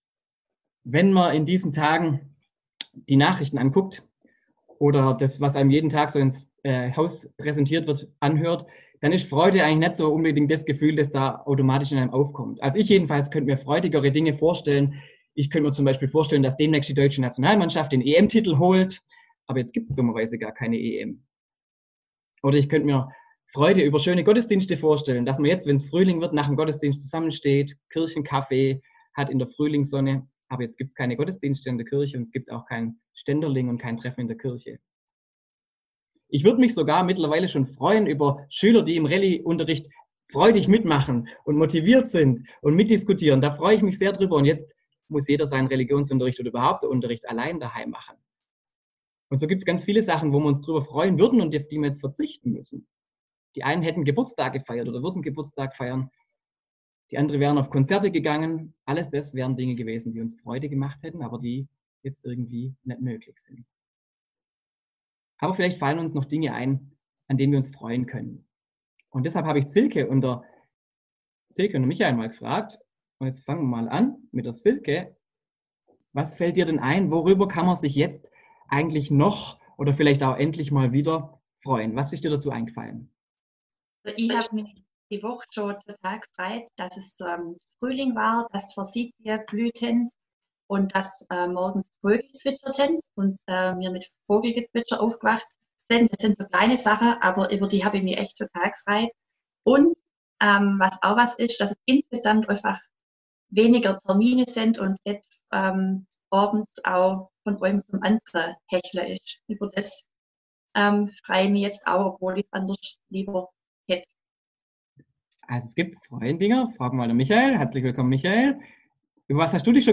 Predigt vom Online-Gottesdienst am Sonntag Lätare.